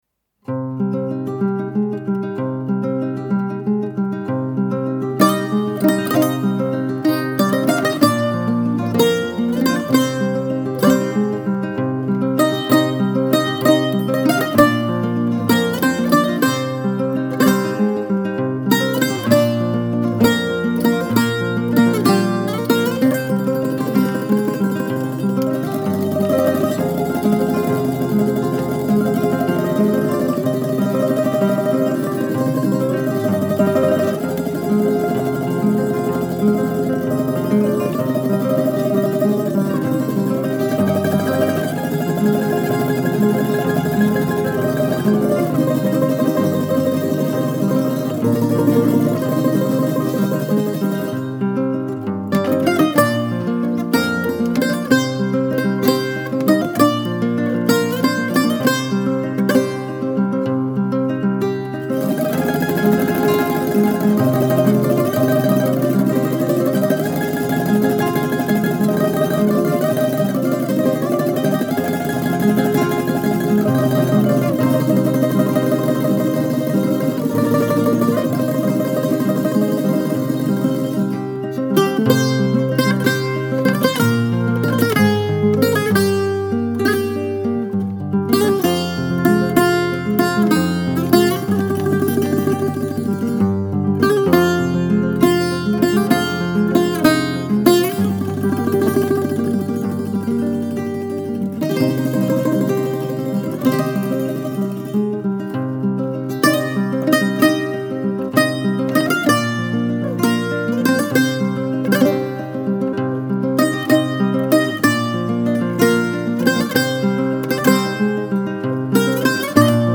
سبک آرامش بخش , ملل , موسیقی بی کلام
موسیقی بی کلام بوزوکی موسیقی ملل